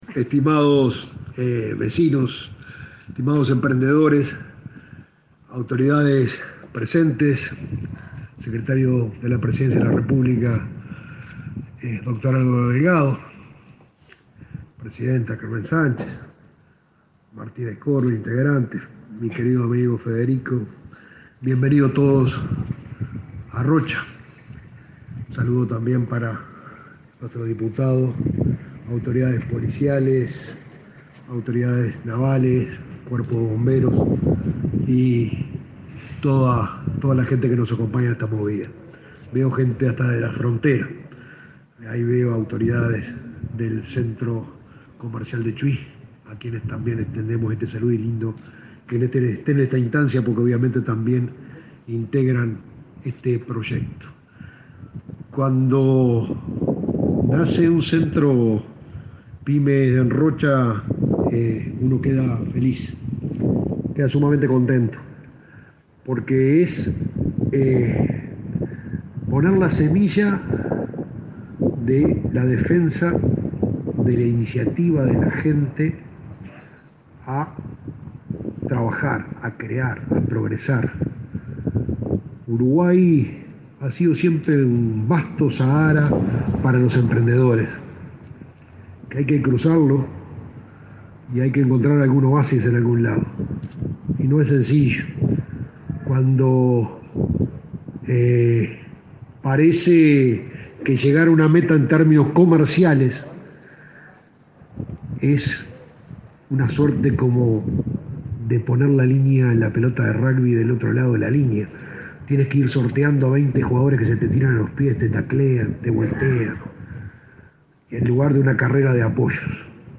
Conferencia de prensa por la inauguración del Centro Pyme en Rocha
Participaron en el acto el secretario de Presidencia, Álvaro Delgado; la presidenta de ANDE, Carmen Sánchez, y el intendente departamental Alejo Umpiérrez.